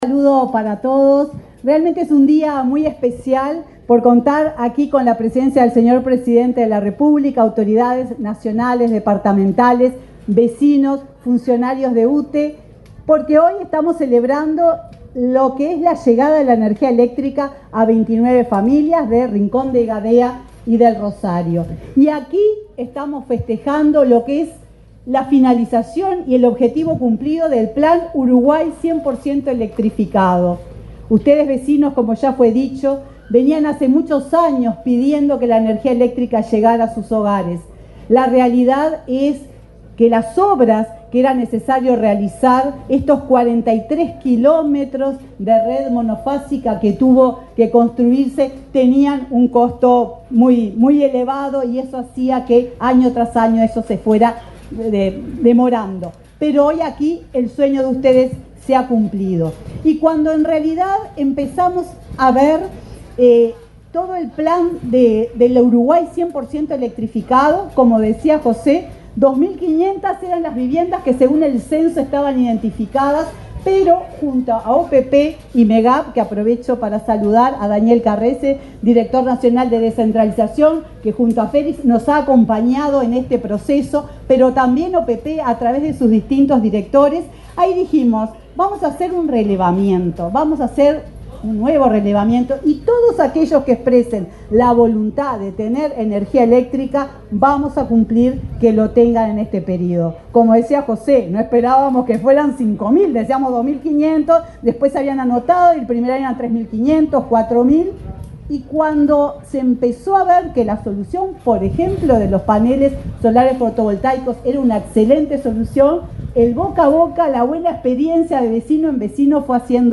Palabras de la presidenta de UTE, Silvia Emaldi
Palabras de la presidenta de UTE, Silvia Emaldi 27/02/2025 Compartir Facebook X Copiar enlace WhatsApp LinkedIn La presidenta de la UTE, Silvia Emaldi, participó en la inauguración de obras de electrificación rural en Rincón de Gadea, departamento de Treinta y Tres.